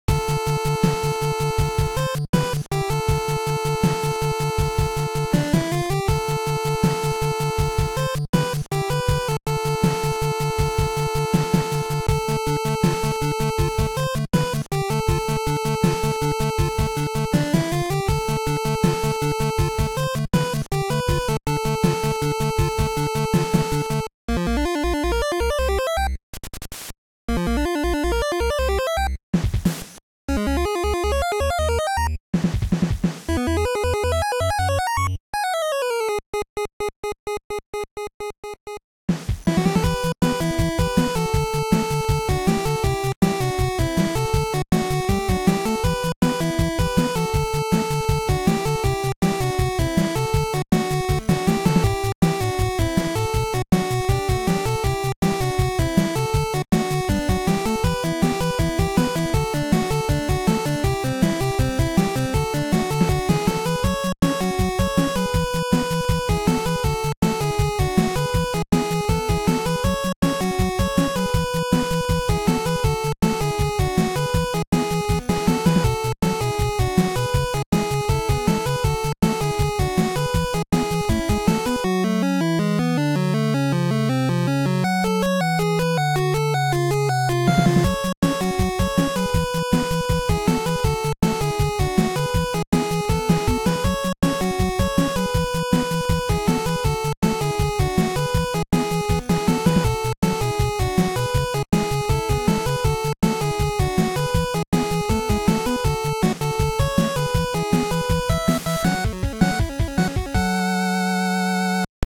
Made using Dn-Famitracker.